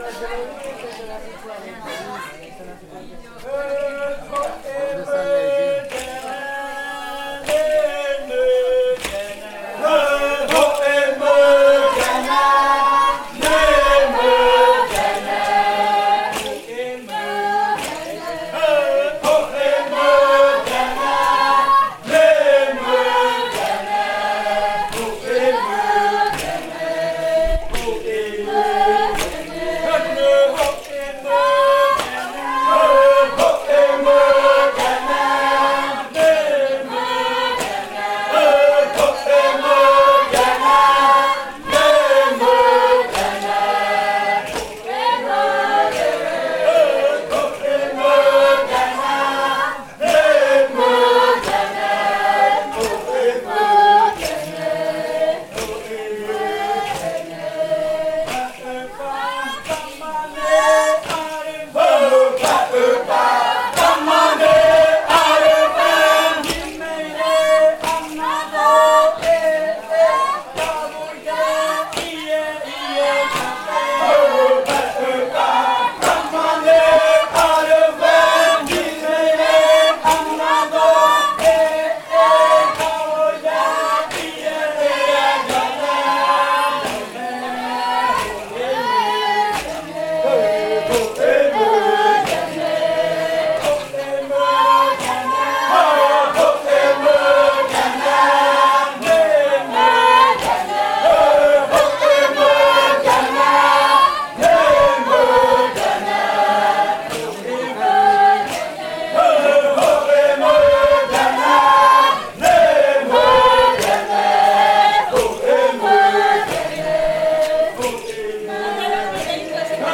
53. Canto después de medianoche de la variante muruikɨ
con el grupo de cantores bailando en Nokaido.
with the group of singers dancing in Nokaido.